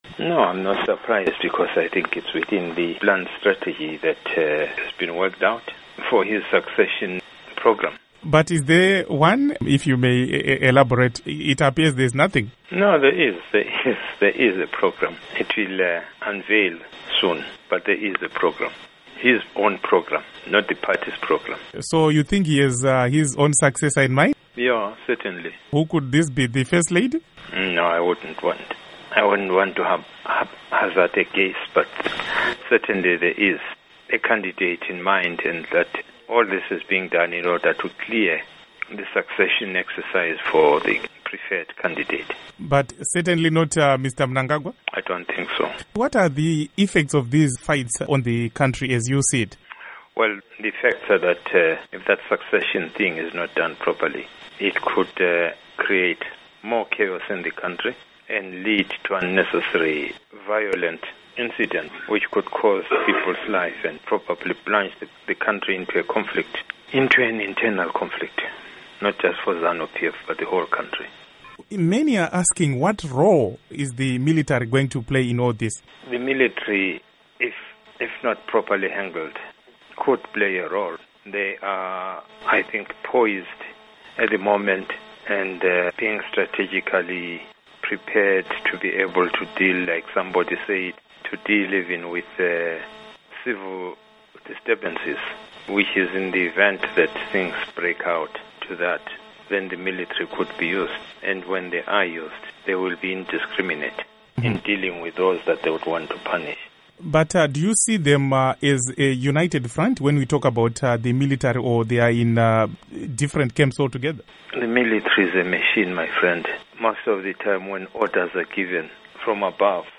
Interview With Dumiso Dabengwa on Zanu PF Succession